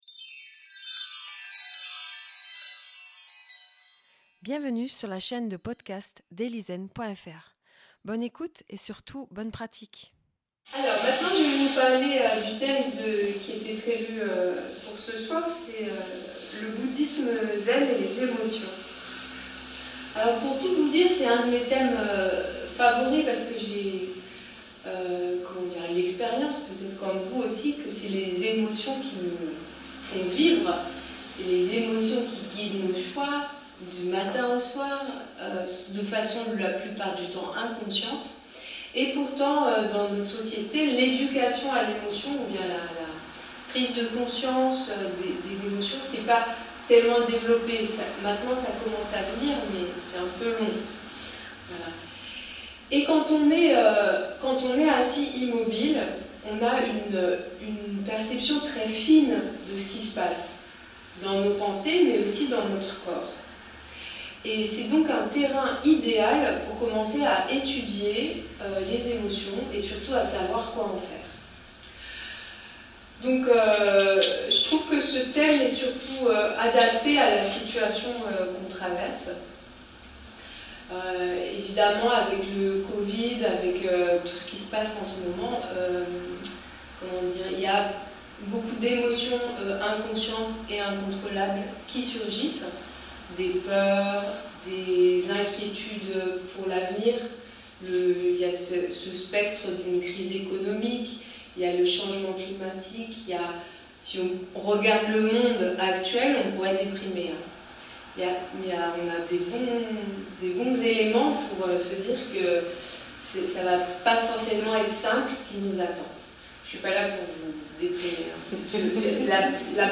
Enregistrés le jeudi 15 octobre au Centre zen du Luxembourg.
Une petite conférence – synthétique – pour savoir que faire des émotions, leur sens et ce que propose l’enseignement bouddhiste.